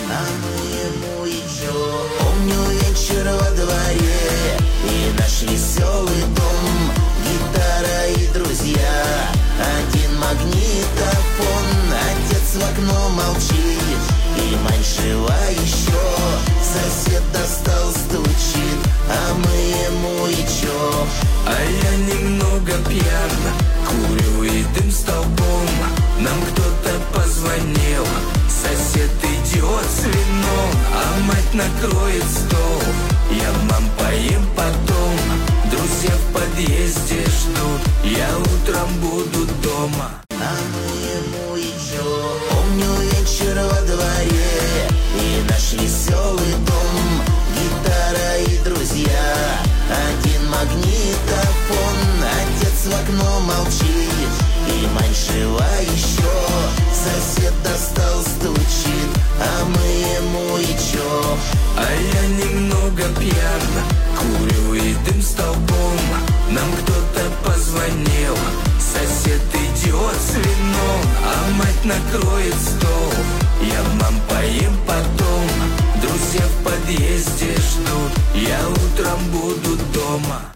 Качество: 320 kbps, stereo
Русские треки, Шансон